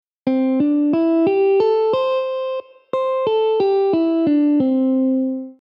(C-D-E-G-A)
Major-Pentatonic-AUDIO.mp3